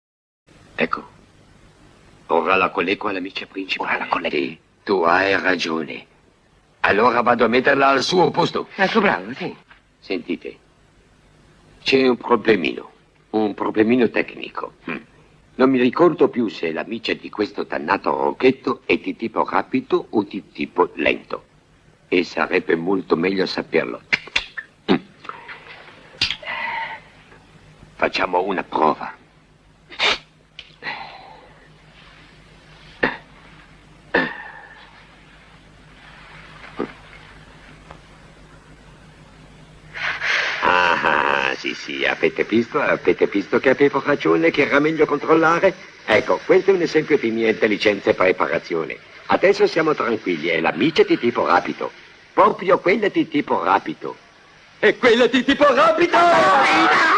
voce di Silvio Noto nel film "Per favore,  non toccate le vecchiette!", in cui doppia Kenneth Mars.